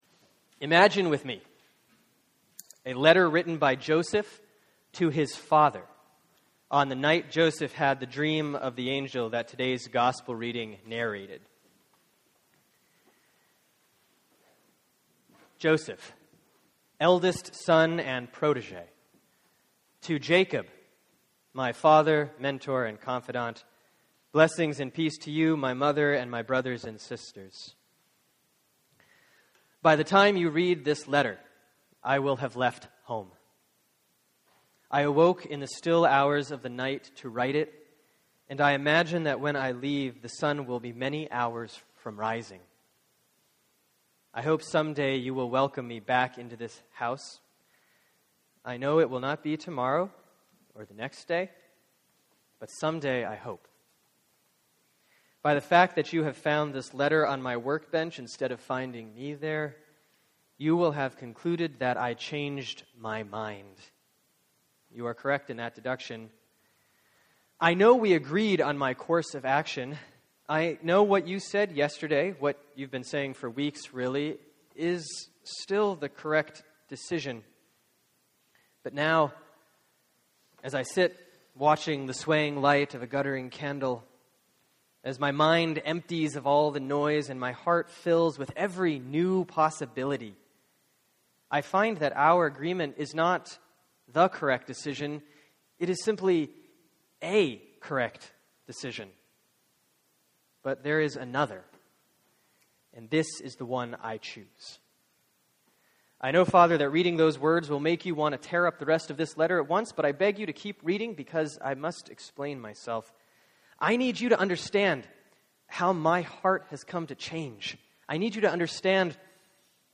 Sermon for December 22, 2013 || Advent 4A || Matthew 1:18-25